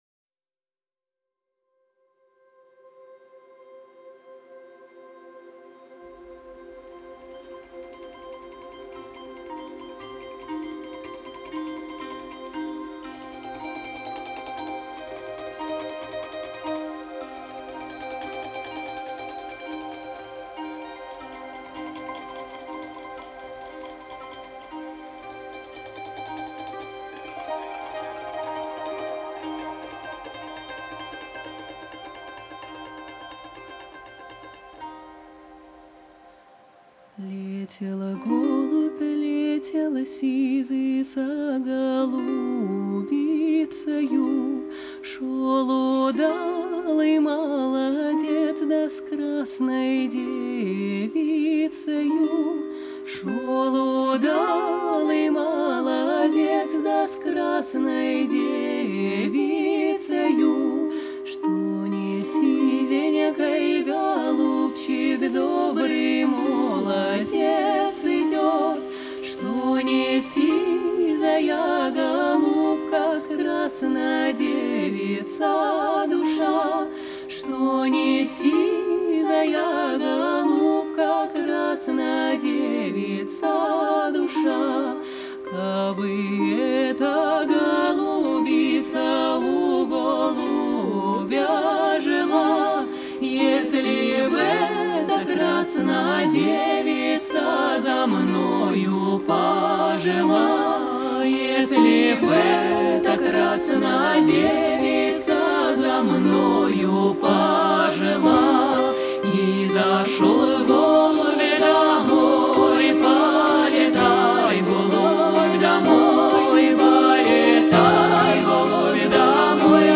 Жанр: Народная музыка